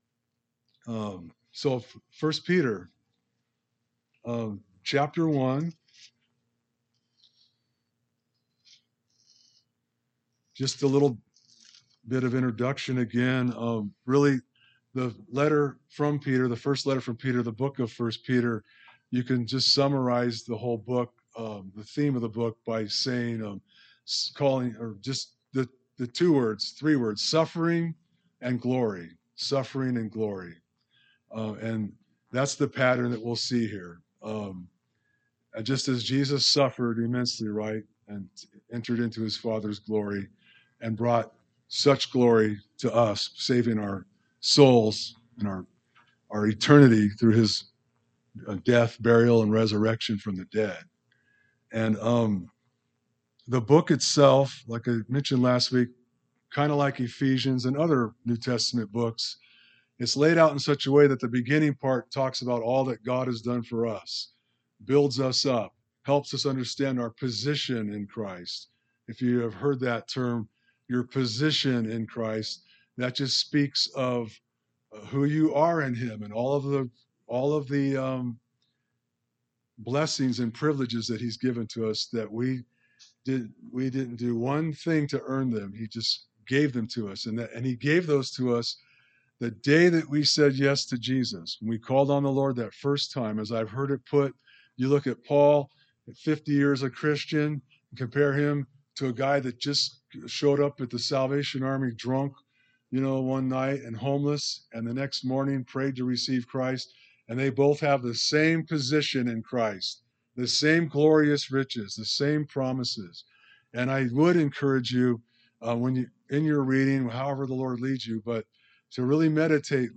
A message from the series "1 Peter."